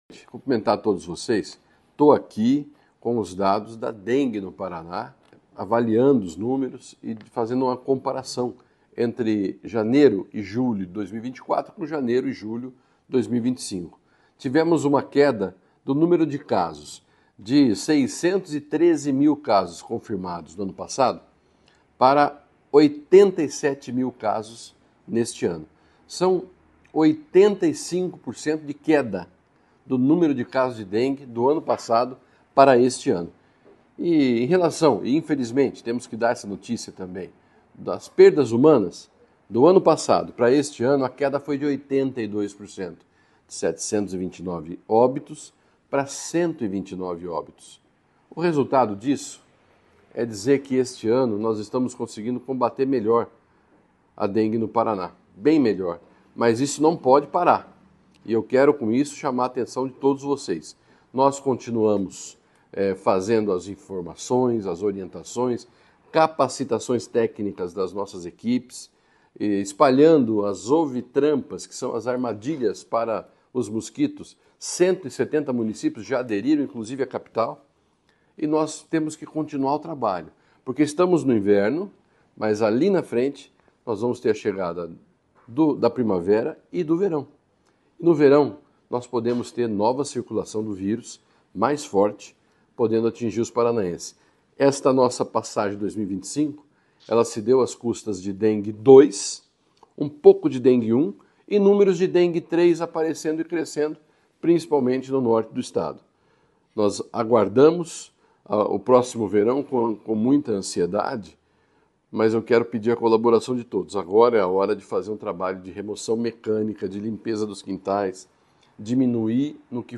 Sonora do secretário Estadual da Saúde, Beto Preto, sobre a redução de casos, mortes e notificações da dengue neste ano em relação a 2024